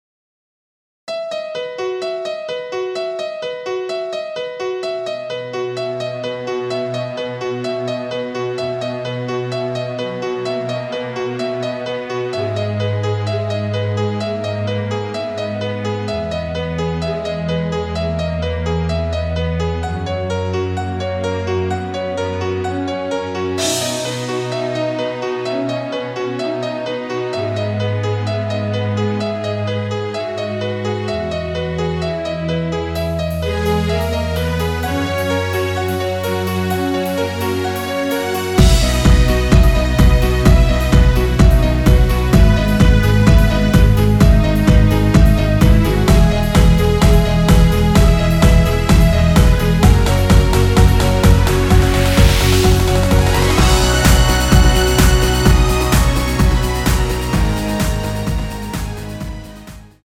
엔딩이 페이드 아웃이라 엔딩을 만들어 놓았습니다.(멜로디 MR 미리듣기 확인)
◈ 곡명 옆 (-1)은 반음 내림, (+1)은 반음 올림 입니다.
앞부분30초, 뒷부분30초씩 편집해서 올려 드리고 있습니다.